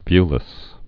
(vylĭs)